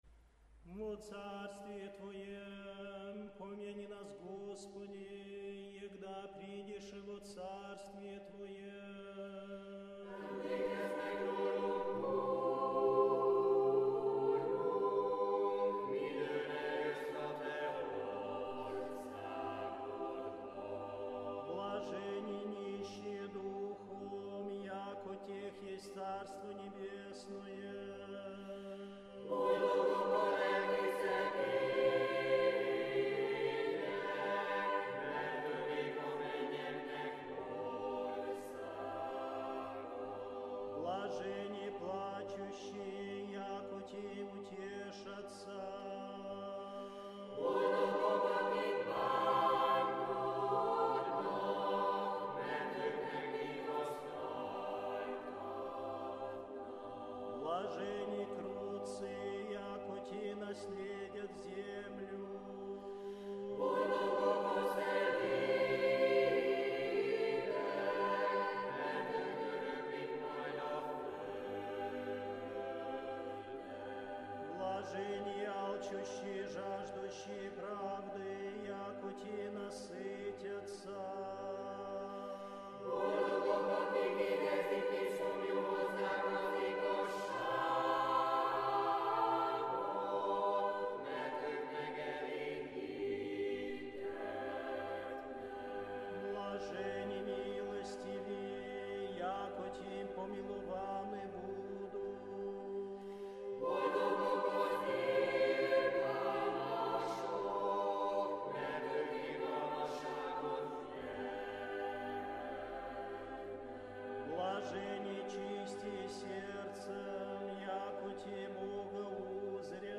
“B” Liturgia – négyszólamú ünnepi dallamokkal (mp3)